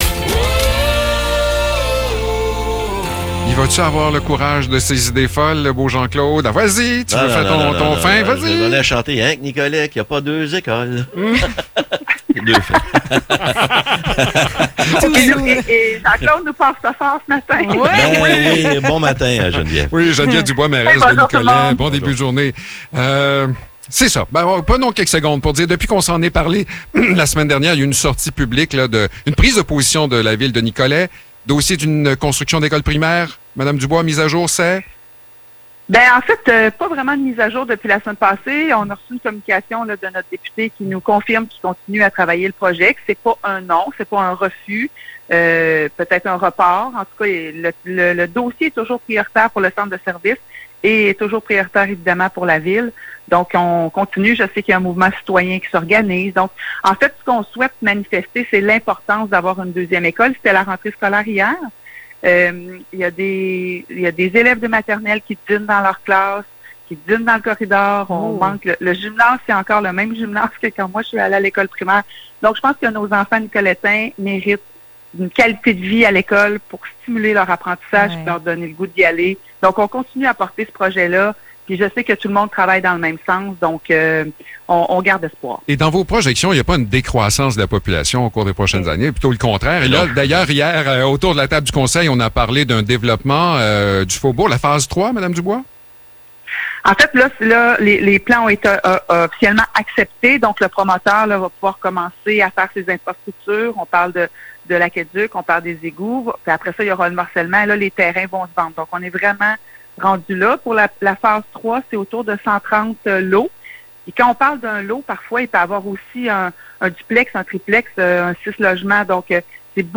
Échange avec la mairesse de Nicolet